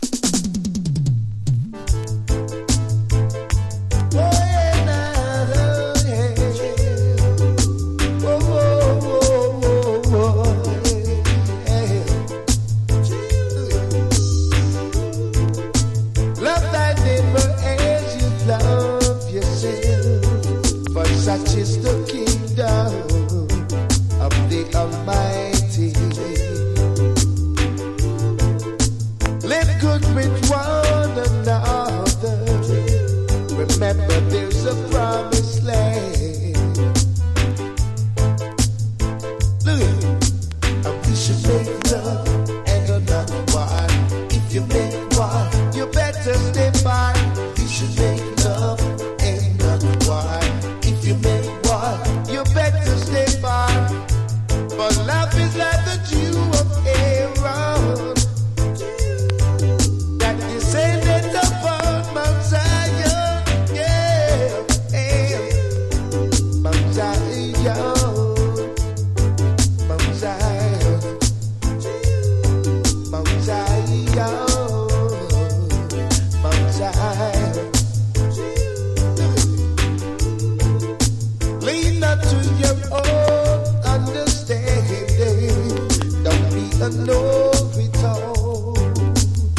# DANCE HALL